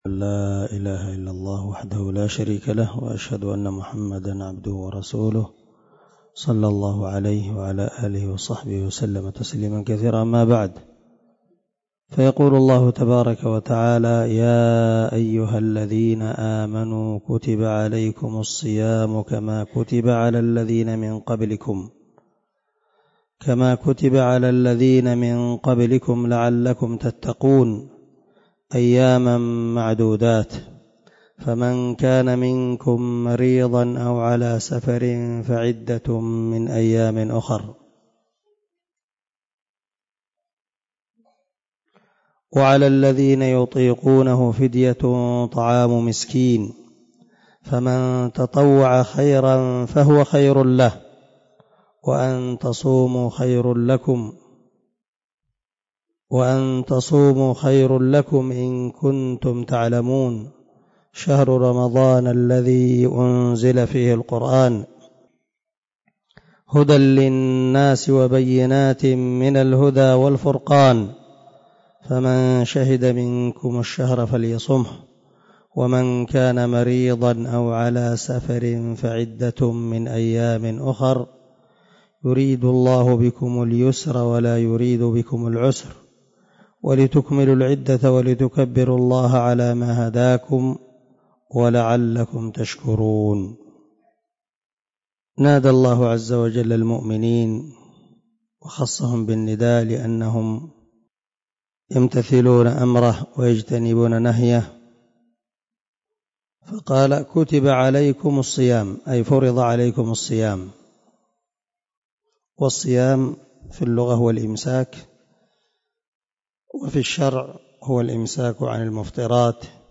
082الدرس 72 تفسير آية ( 183 – 185 ) من سورة البقرة من تفسير القران الكريم مع قراءة لتفسير السعدي
دار الحديث- المَحاوِلة- الصبيحة.